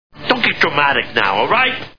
The Sopranos TV Show Sound Bites